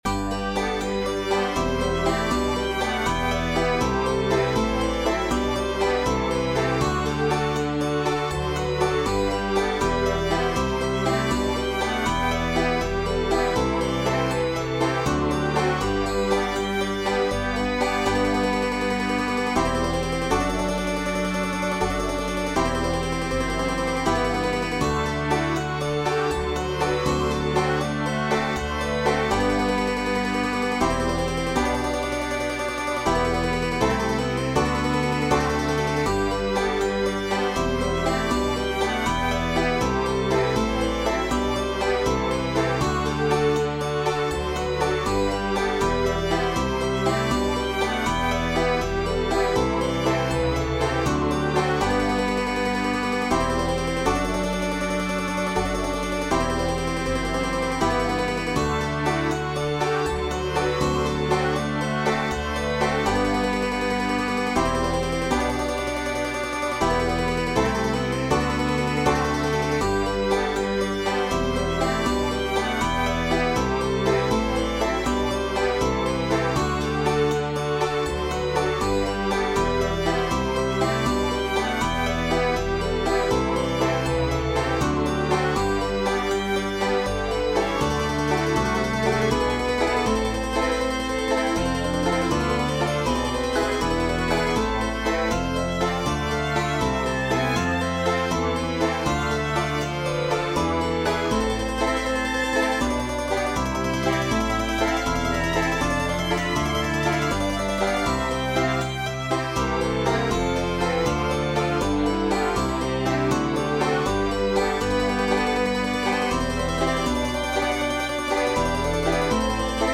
Voicing/Instrumentation: Double Bass , Guitar Chords Available , Instrument Ensemble , Viola , Violin Duet/Violin Ensemble Member(s) We also have other 6 arrangements of " Jesu, Joy of Man's Desiring ".